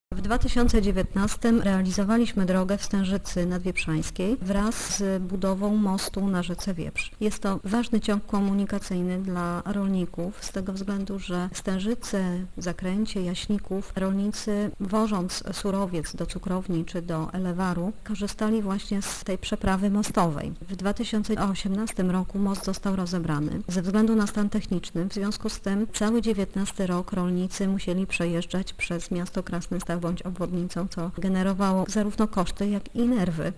Edyta Gajowiak-Powroźnik przypomina, że Gmina Krasnystaw korzysta ze środków FDS nie pierwszy raz: